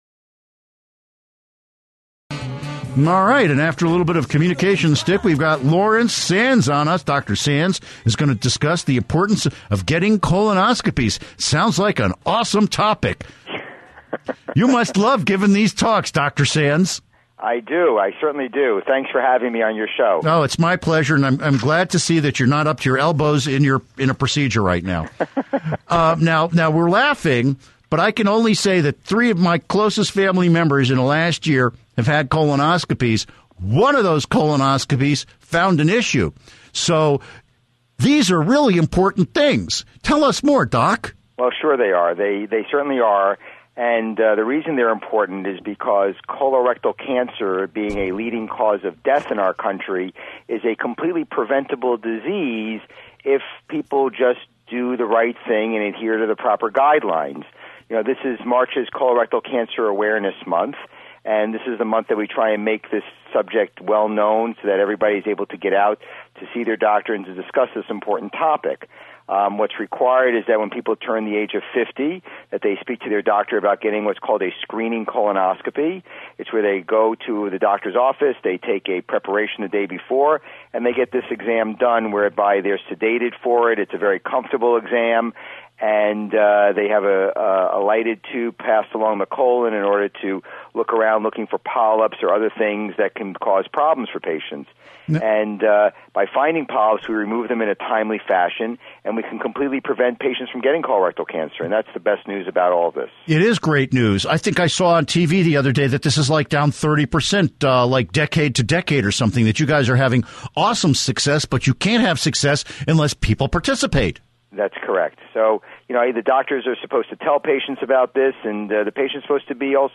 Interview Segment